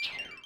Add sound effects!
teleport.ogg